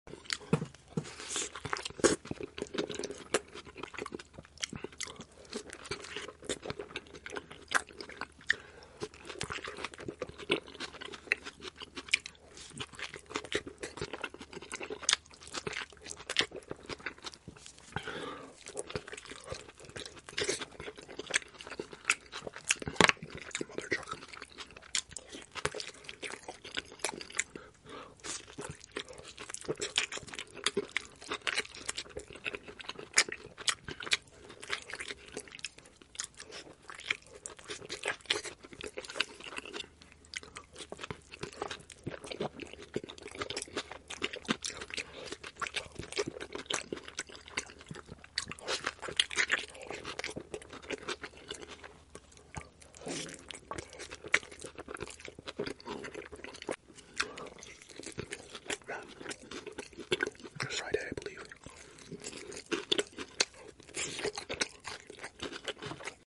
rld’s largest French fries cut sound effects free download
rld’s largest French fries cut ASMR satisfaction